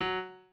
piano2_27.ogg